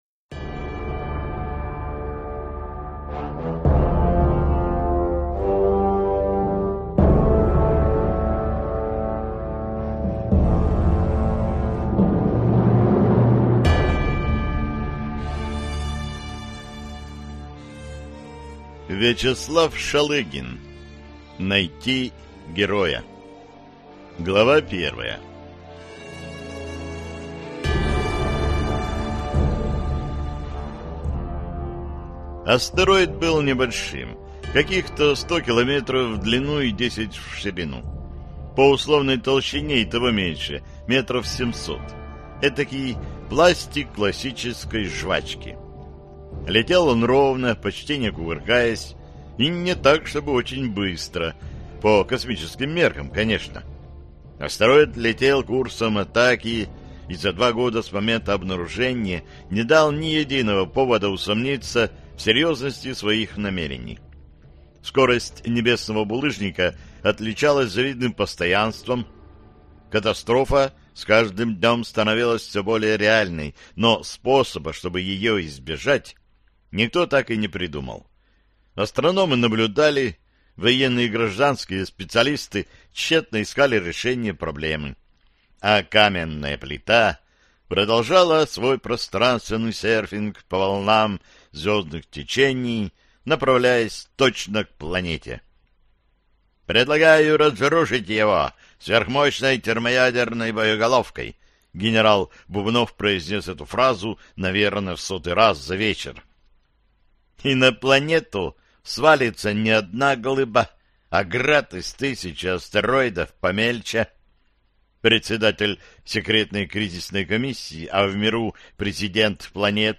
Aудиокнига Найти героя